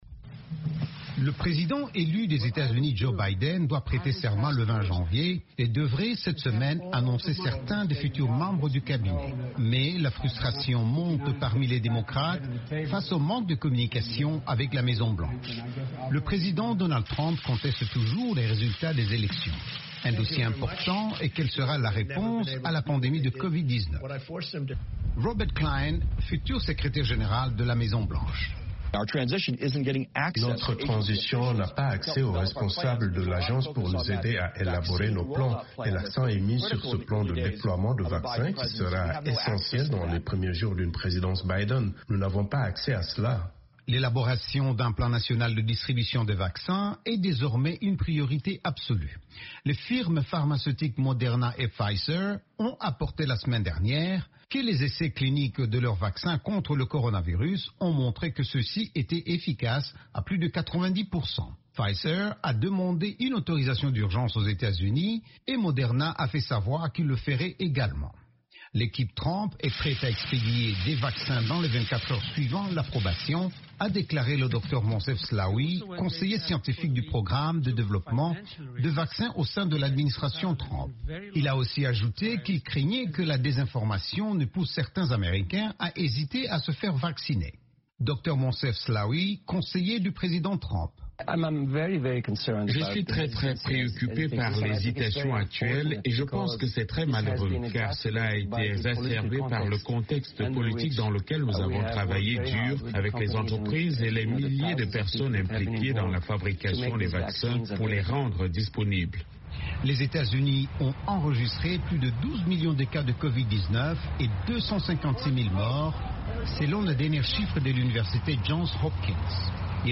Le président américain Donald Trump conteste toujours les résultats des élections du 3 novembre, si bien qu’il y a peu de communication entre son administration et la nouvelle équipe Biden, y compris sur la réponse à la pandémie de Covid-19. Le reportage